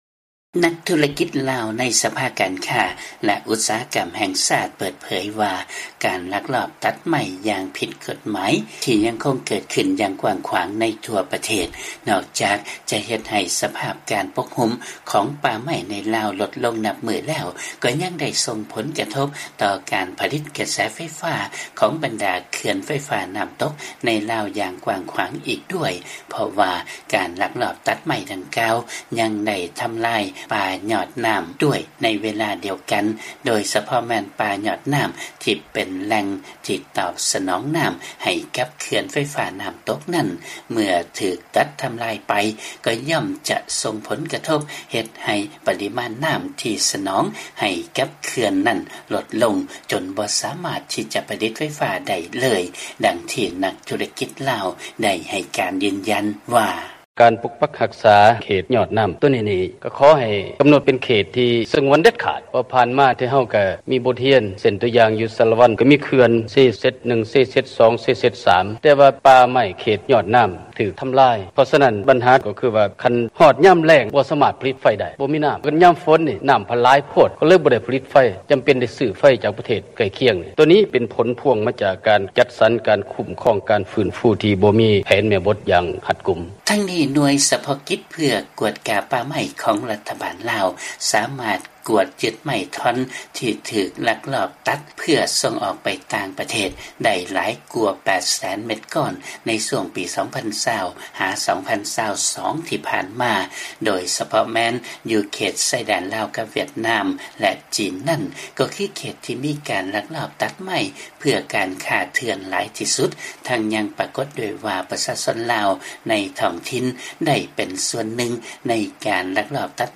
ມີລາຍງານເລື້ອງນີ້ຈາກບາງກອກ.